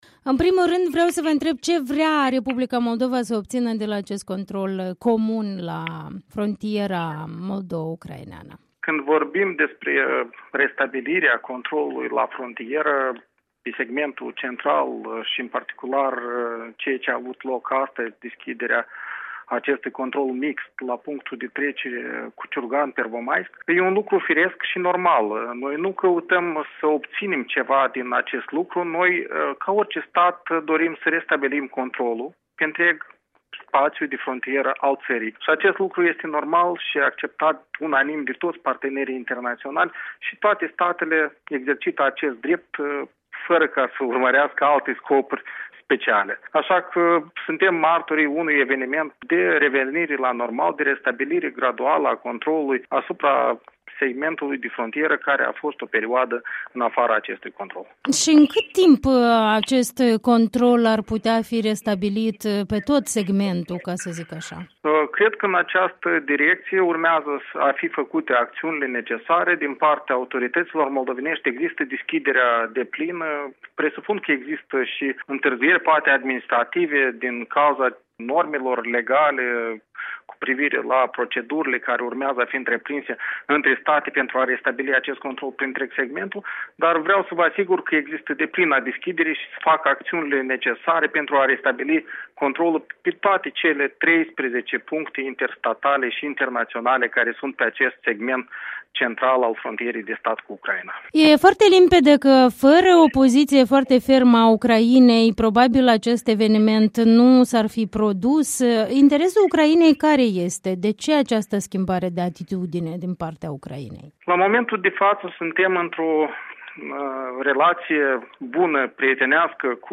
Interviu cu Gheorghe Bălan